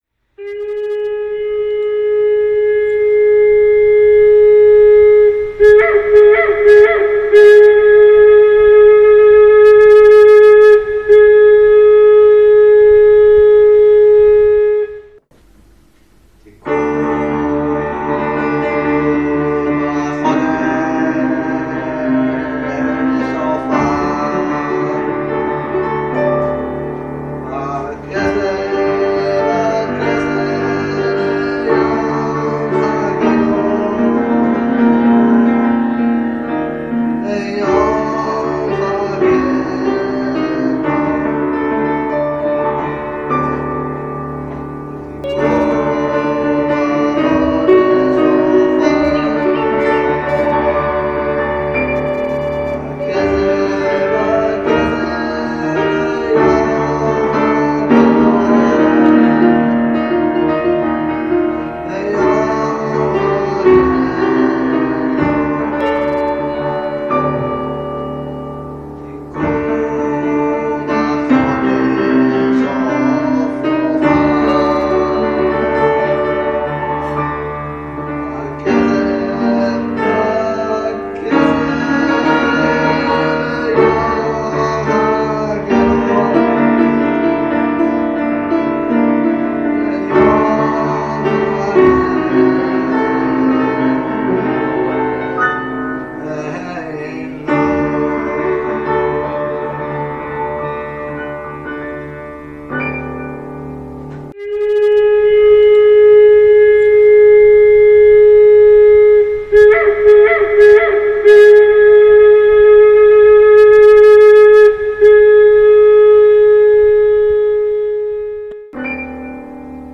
מטושטש מאד אבל יפה...!
זה הקלטה על הקלטה והאיכות קצת נפגמת.